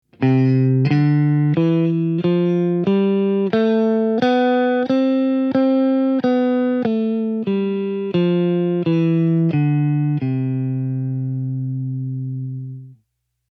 Durskala
C-dur opp_og_ned Kapittel_4:_Rockens_historie